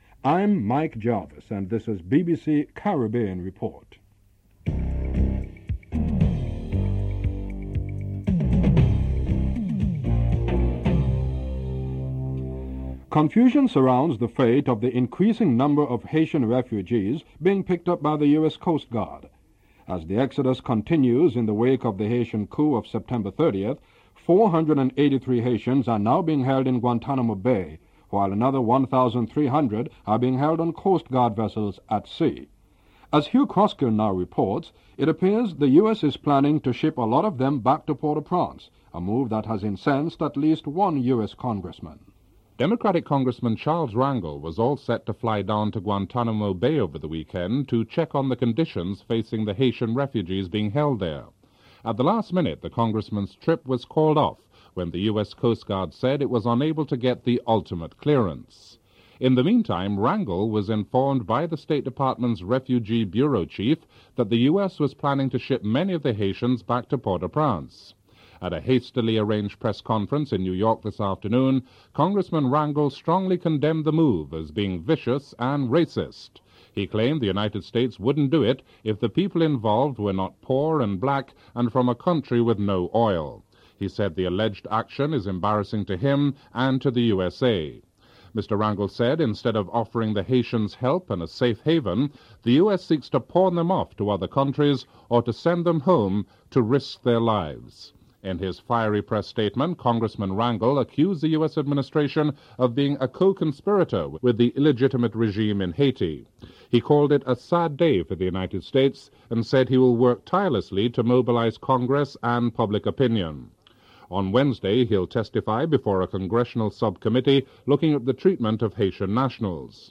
Report commences during the headlines.
1. Headlines (00:00-00:15)